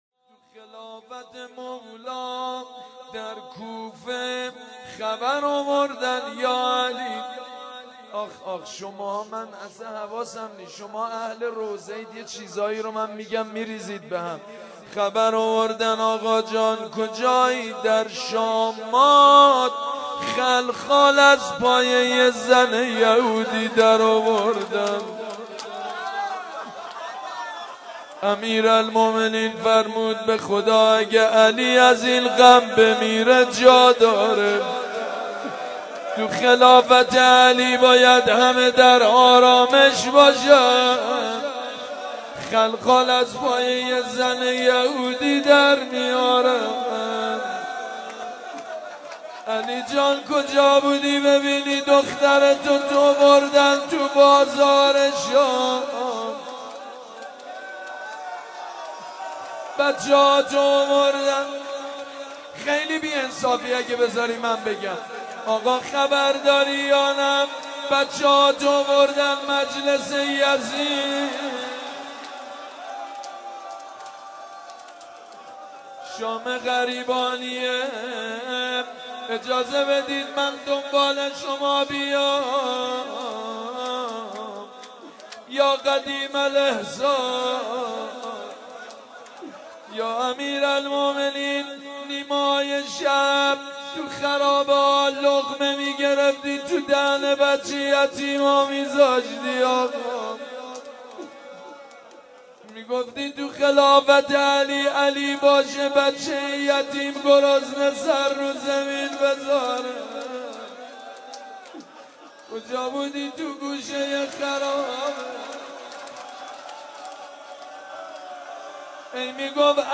شب ۲۲ رمضان ۹۳ ،هیئت ریحانه الحسین
مناجات
روضه